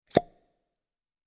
Звук внезапного исчезновения
• Категория: Исчезновение, пропадание
• Качество: Высокое